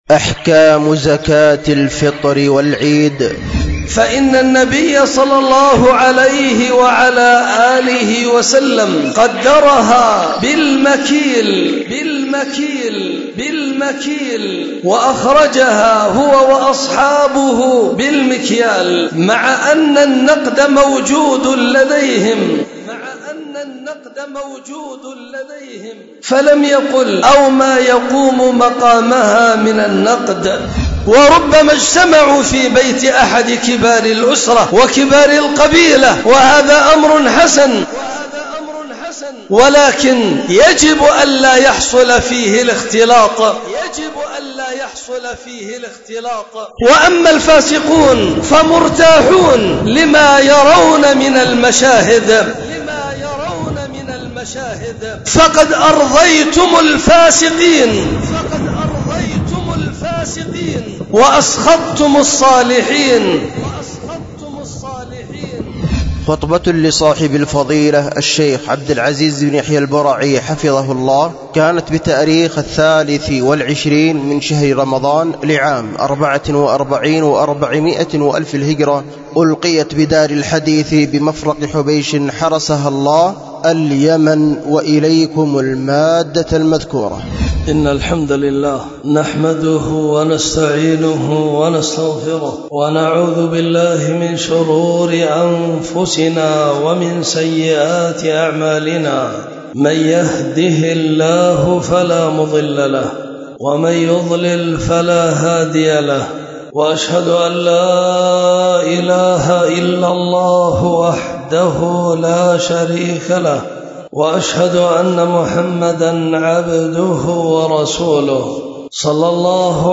خطبة
ألقيت بدار الحديث بمفرق حبيش